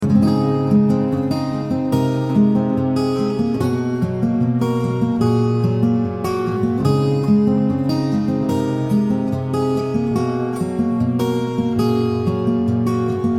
I recall trying to learn Phil Keaggy's Fare Thee Well--a challenging piece in a very alternate tuning (E-G#-D-F#-B-E) with the capo on the third fret.
the notes for the chorus and realized I had found something special (I later discovered it cannot be played in standard tuning).
guitar_chorus.mp3